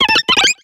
Grito de Happiny.ogg
Grito_de_Happiny.ogg